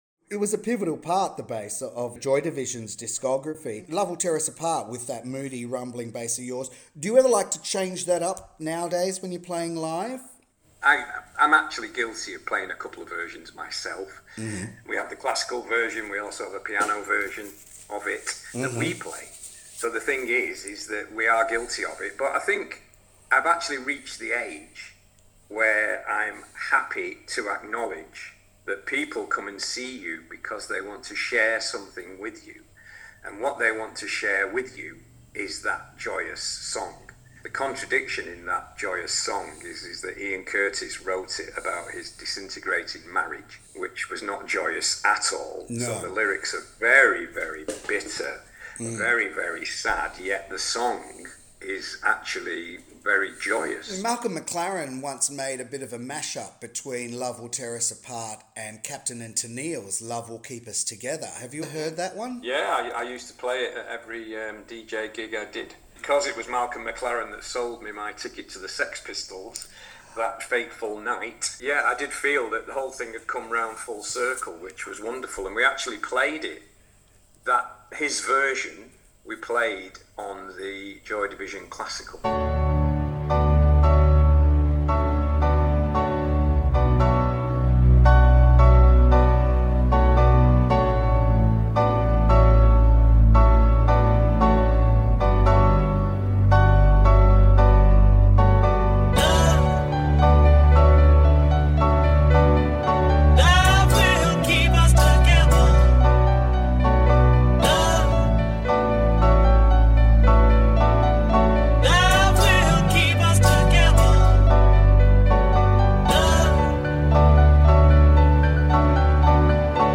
peter-hook-interview-mp3.mp3